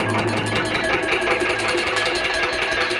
Index of /musicradar/rhythmic-inspiration-samples/80bpm
RI_DelayStack_80-04.wav